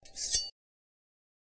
knife.ogg